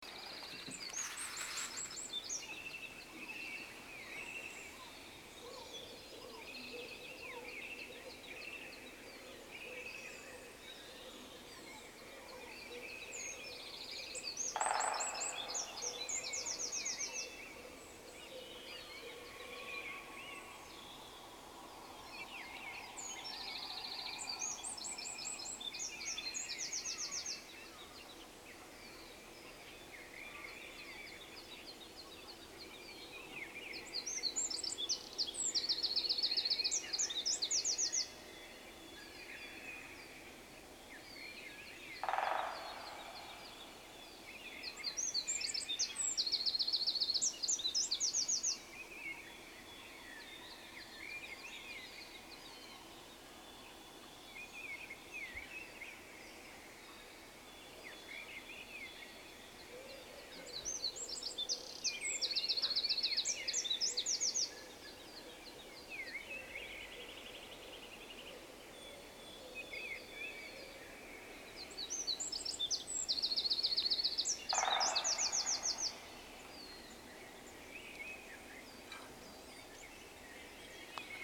Early-Morning-Swedish-Songbirds.mp3